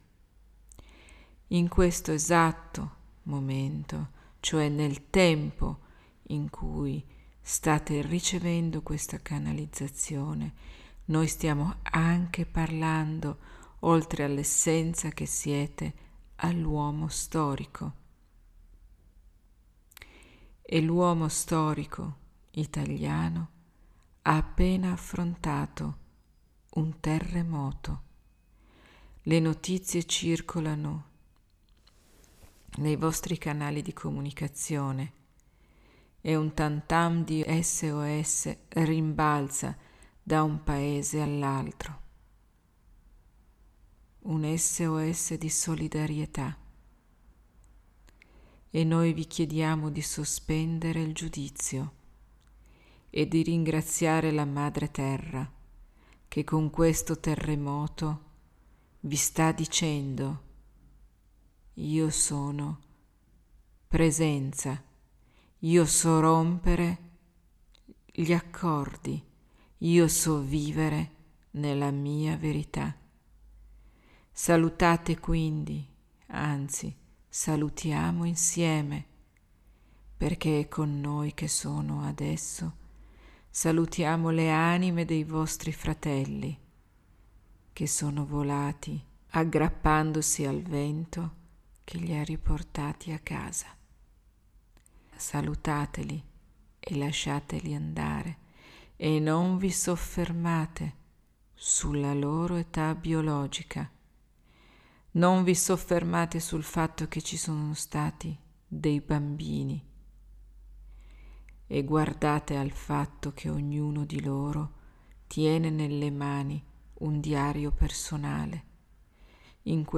Un Messaggio sul Terremoto